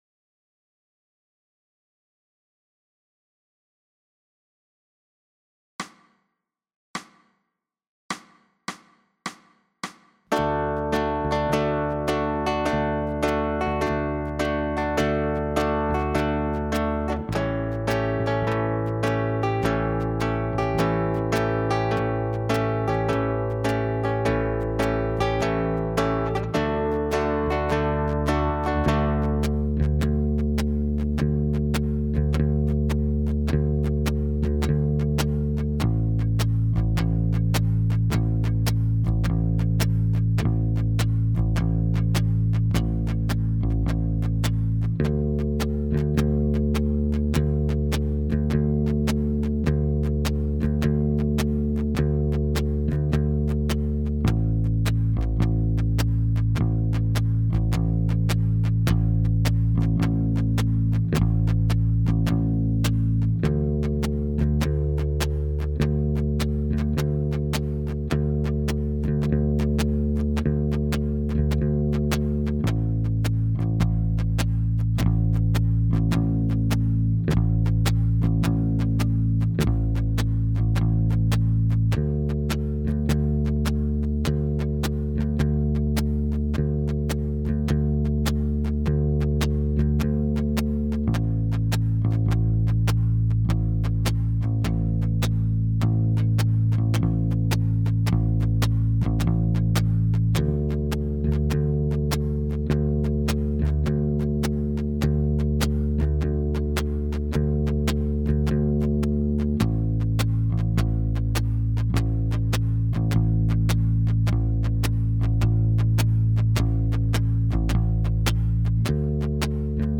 Ja, Du siehst eine Konzertgitarre, die mit Nylonsaiten bespannt ist, auch wenn sie eine dafür ungewöhnliche Form hat.
Strum-Viertel-Achtel-Konzertgit.mp3